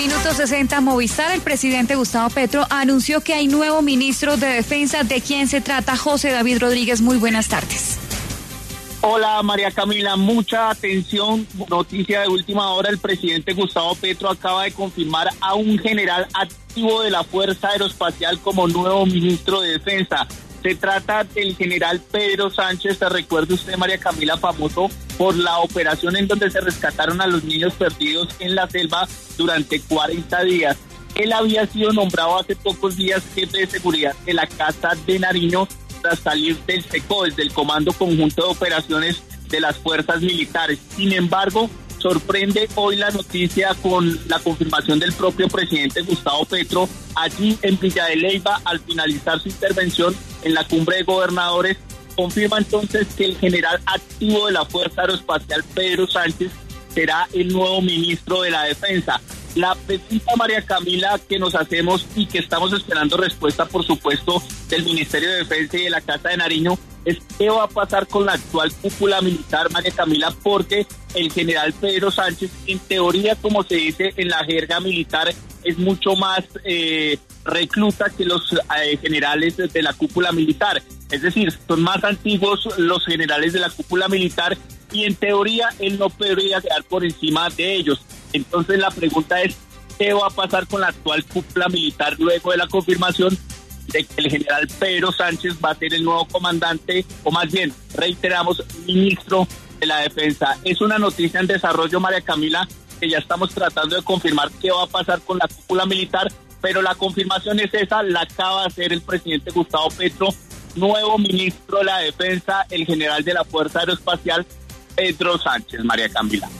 Durante la instalación de la Cumbre de Gobernadores en el municipio de Villa de Leyva, el presidente Gustavo Petro anunció que el nuevo ministro de Defensa será el general de la Fuerza Aeroespacial, Pedro Sánchez.